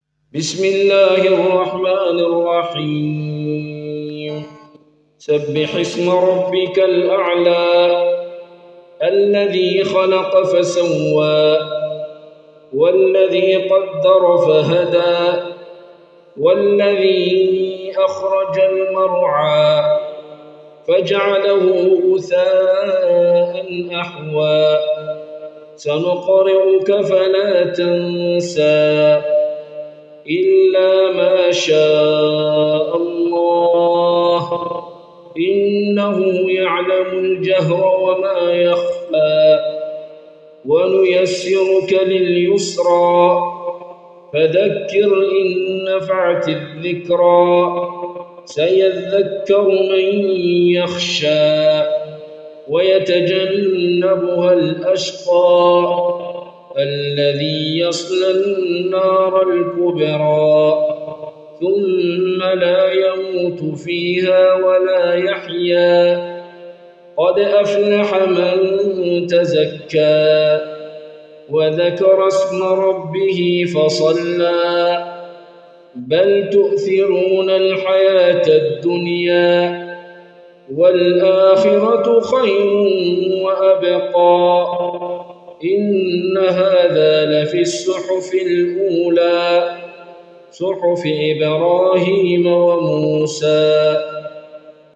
قراءة